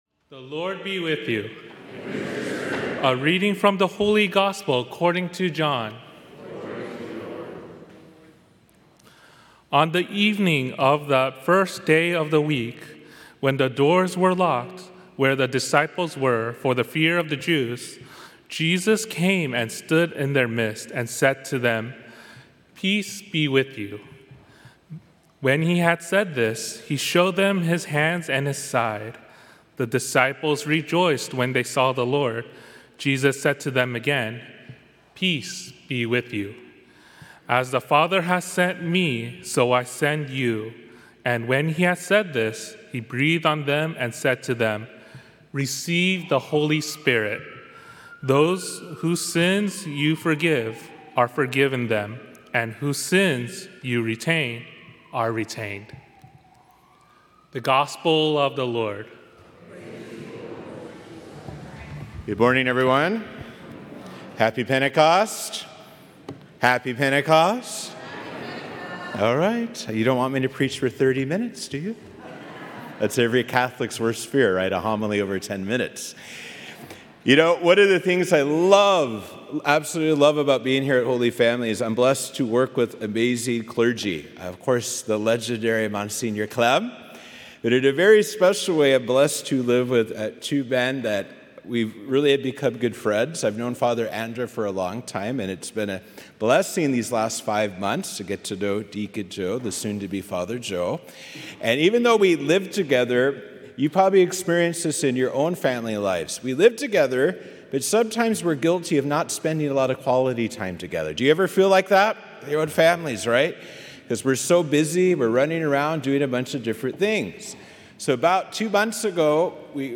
Solemnity of Pentecost, May 19, 2024, 8:00 Mass